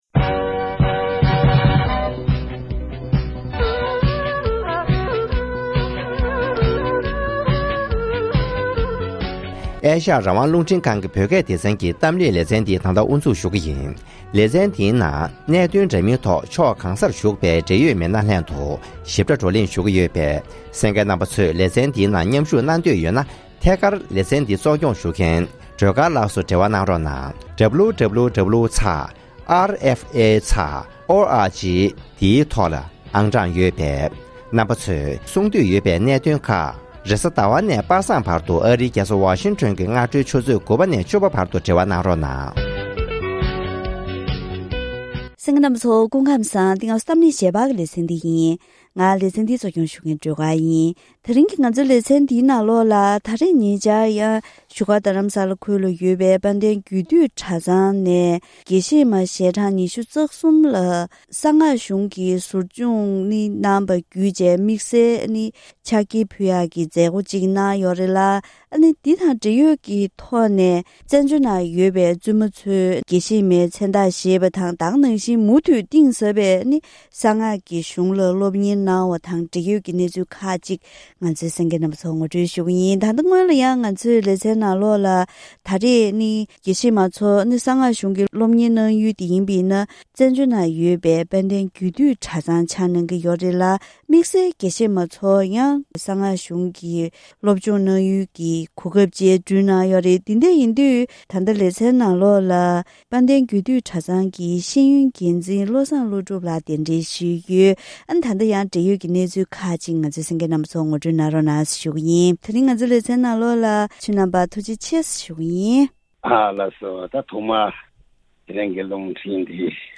༄༅༎དེ་རིང་གི་གཏམ་གླེང་ཞལ་པར་ལེ་ཚན་ནང་བཙན་བྱོལ་ནང་འདས་པའི་ལོ་ངོ་དྲུག་བཅུའི་རིང་བཙུན་མའི་དགོན་པ་ཁག་ནང་གཞུང་ཆེན་ལ་སློབ་གཉེར་གནང་རྒྱུ་དབུ་བཙུགས་ནས་དགེ་བཤེས་མའི་མཚན་རྟགས་བཞེས་མཁན་རིམ་པས་ཐོན་བཞིན་ཡོད་ལ།